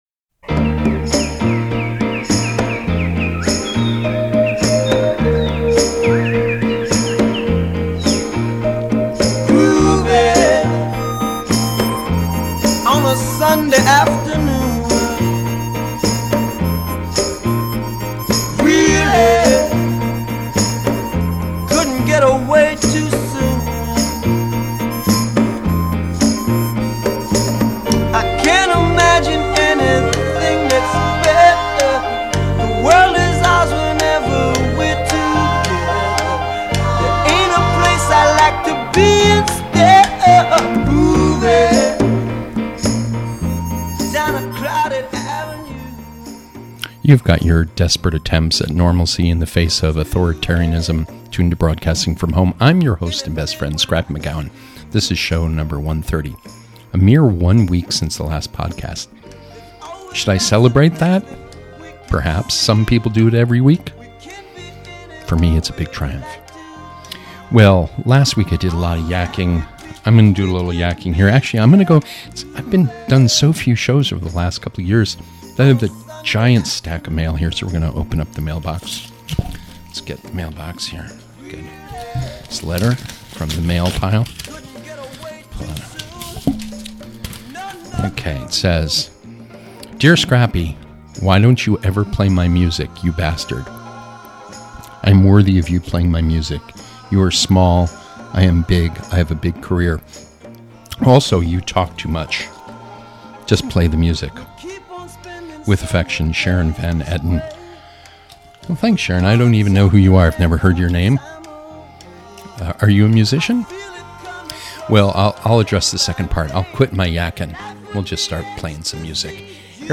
music that is sweet, soulful, funky, and beautiful.